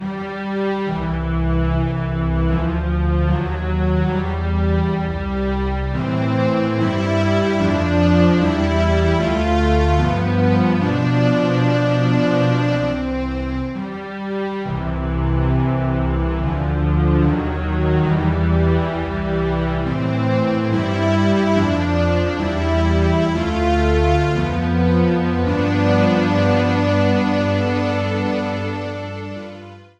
Started at the " Ending " portion, trimmed, and faded out